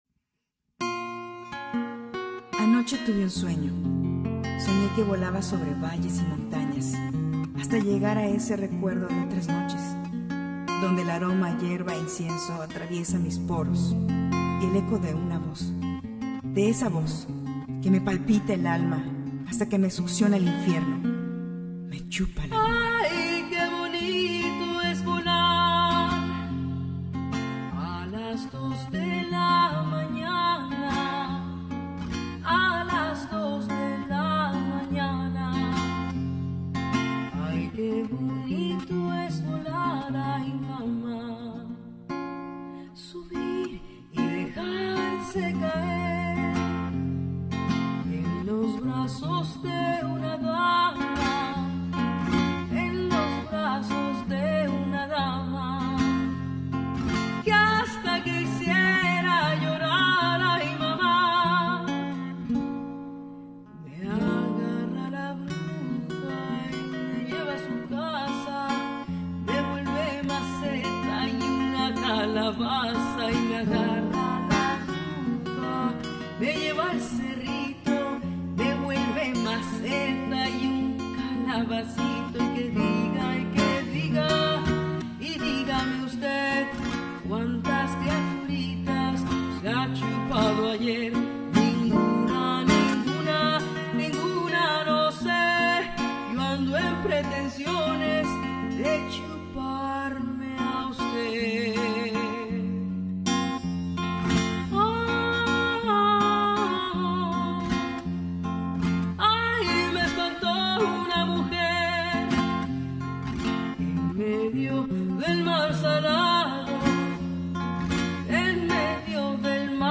Her educated and emotive voice, which conveys a variety of emotions, has undoubtedly contributed to the depth and resonance of the festival experience.
Her voice is absolutely stoning.
She seems to me to be an extraordinary and professional singer, her voice really captivates your inner emotions and makes them shine.
15. eS UNA MUJER CON UNA VOZ ENCANTADORA Y CON UN SENTIMIENTO PROFUNDO AL INTERPRETAR, QUE EMOCIONA HASTA LO MAS PROFUNDO CON SU VOZ Y SU MANERA DE INTERPRETAR LAS CANCIONES.